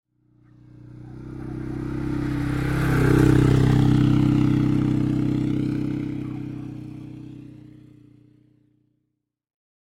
Quad Bike Sound Effect
A large quad bike passes slowly on a dirt road. Slow ATV pass. Motorcycle drive past sound. Authentic close-up recording.
Quad-bike-sound-effect.mp3